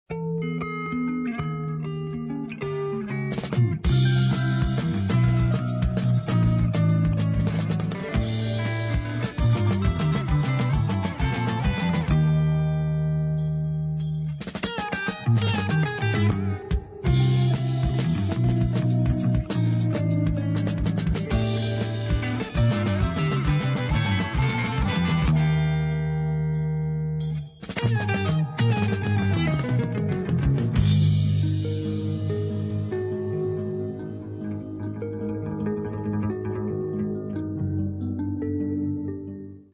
kytary
klávesové nástr
baskytara, perkuse
bicí, perkuse